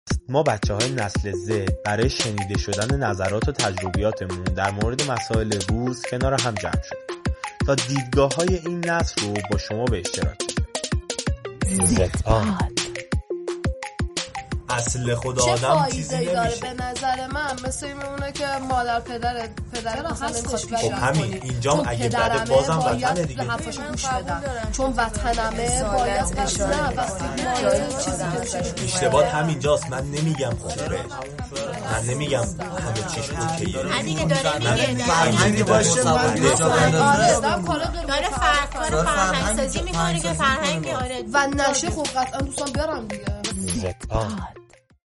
بچه‌های ایرانی در یک دورهمی درباره موضوعات مختلف گفت‌وگو کرده و حاصل این دورهمی را بصورت پادکست منتشر می‌کنند.